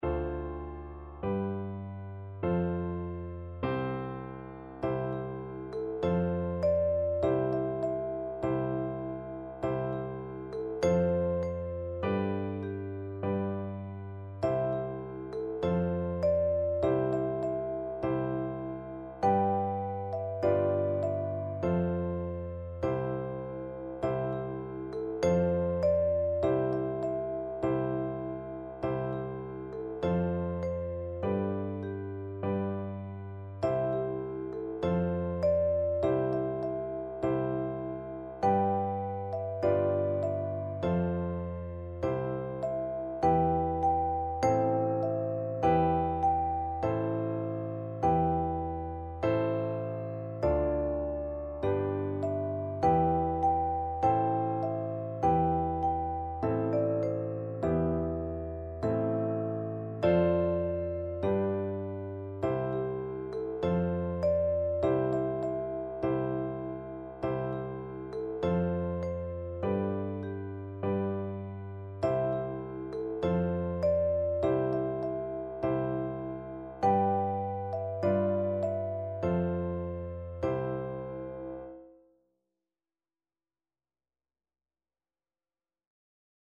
Vibraphone
Nobody Knows the Trouble I've Seen is a spiritual song.
4/4 (View more 4/4 Music)
C major (Sounding Pitch) (View more C major Music for Percussion )